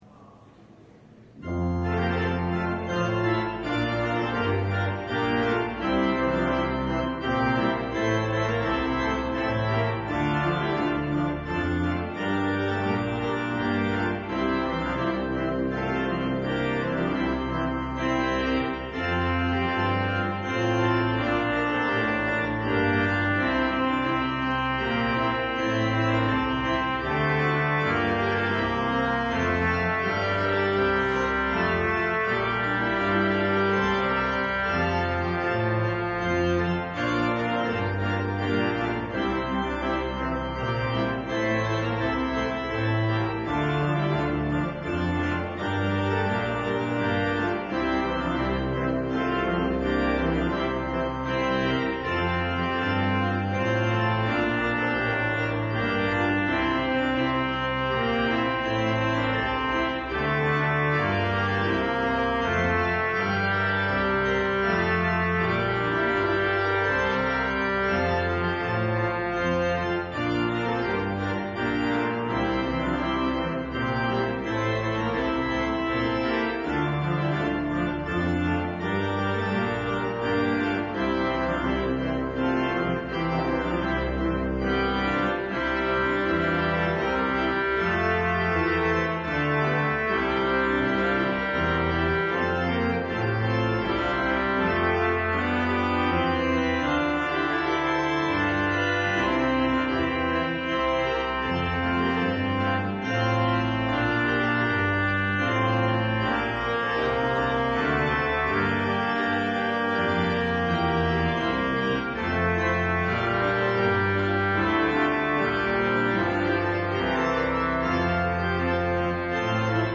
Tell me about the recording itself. LIVE Morning Worship Service - The Prophets and the Kings: The Fall of the House of Ahab